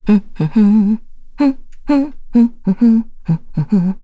Chrisha-Vox_Hum_kr.wav